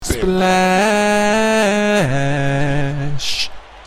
Splash